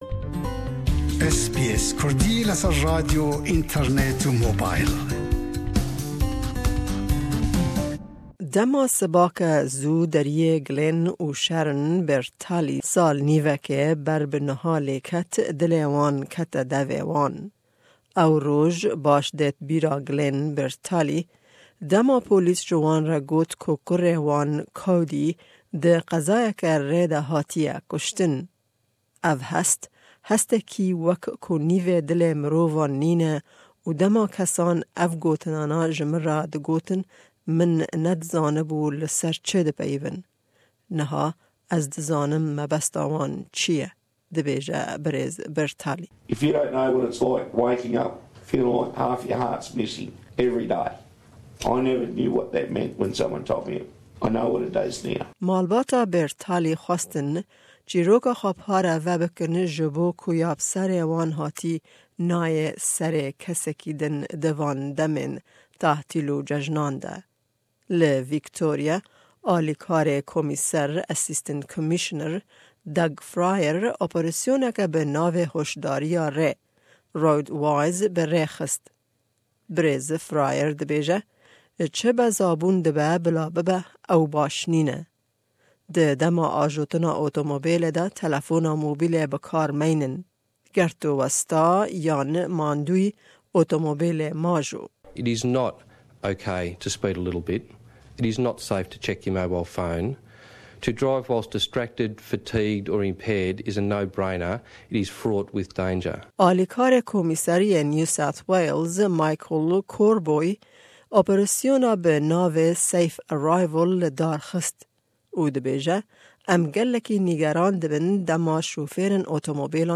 Rapor